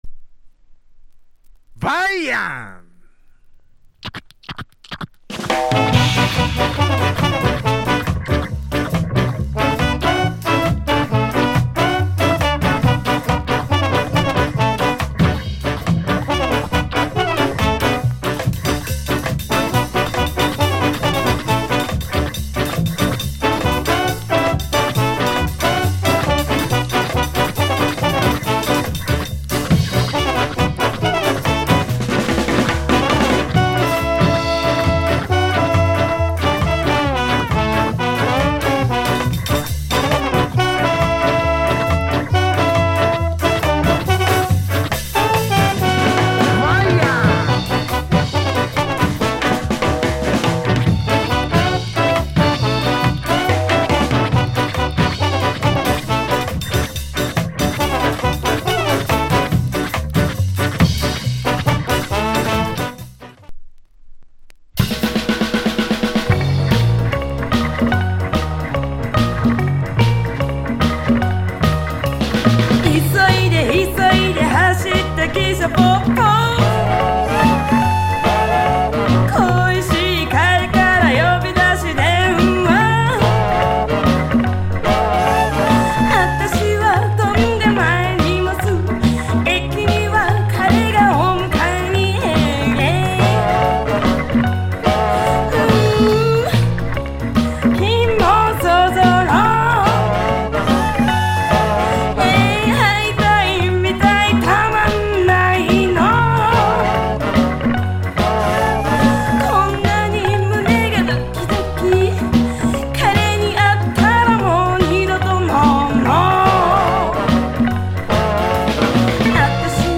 Production JP Genre Ska
Inst [B] Female Vocal Condition M Soundclip ※オファー締切日は1月8日です。